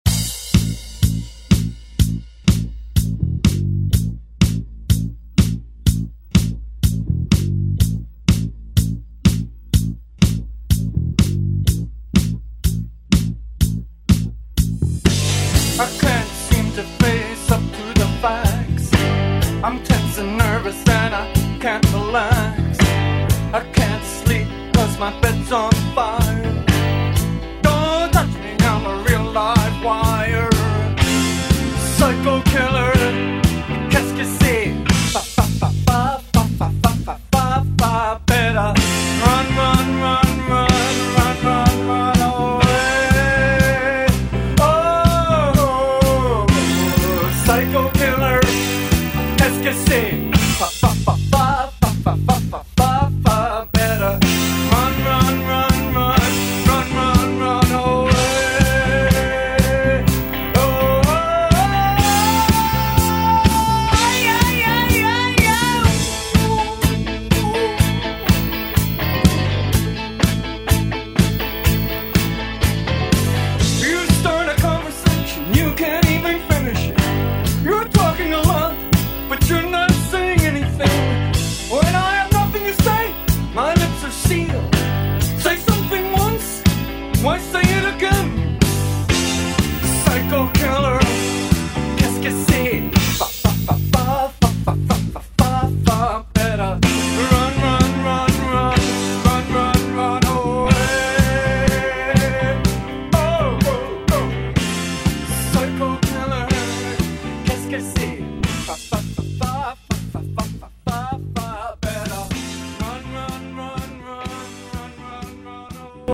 Genres: 90's , DANCE , EDM
BPM: 123